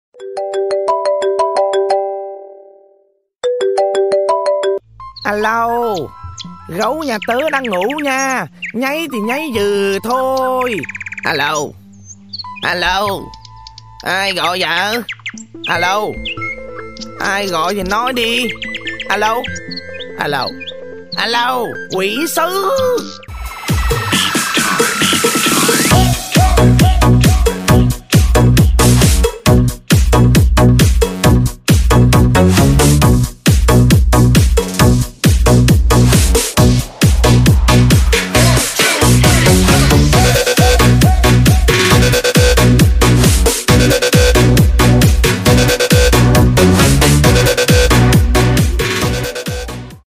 Thể loại nhạc chuông: Nhạc hài hước